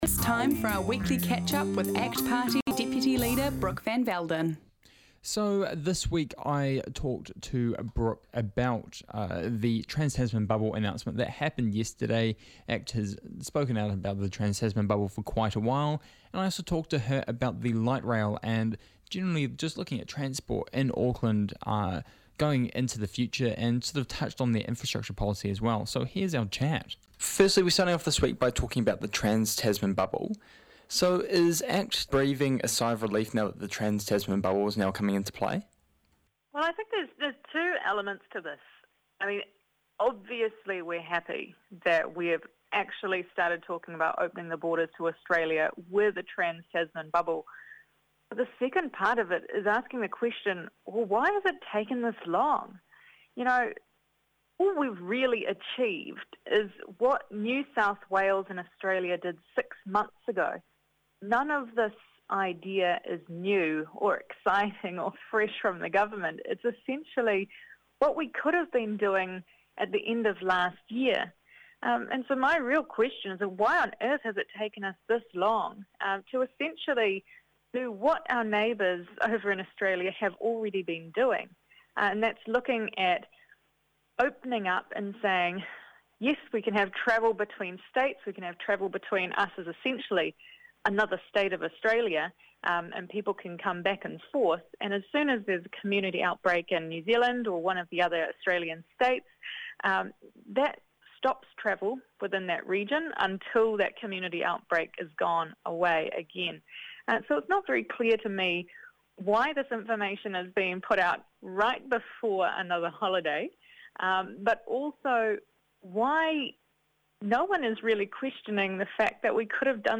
bFM's daily News & Current Affairs show